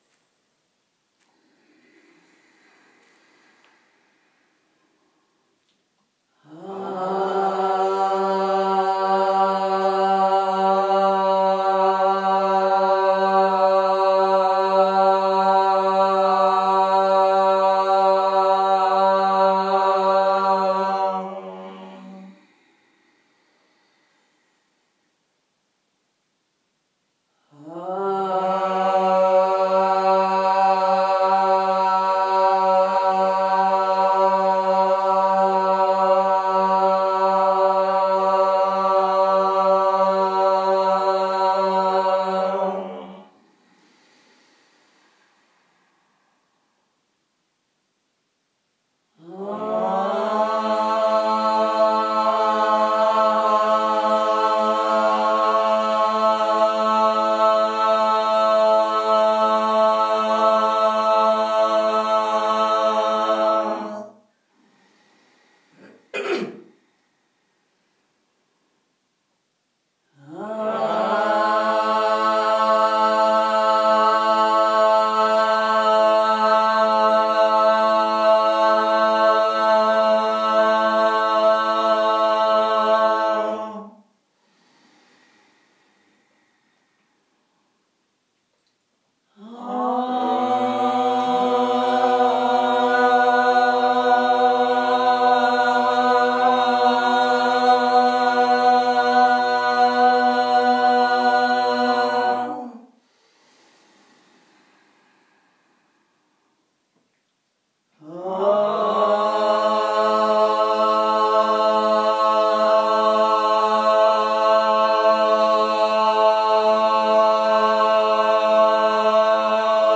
Exhale and create the sound HAAAAAAAAA,  gradually allow the hands and the arms to open, as if you were going to hug a huge Oak tree.
Recording 5/3/2021- Thank you for your hearts and voices
haaaaaaaaaa.m4a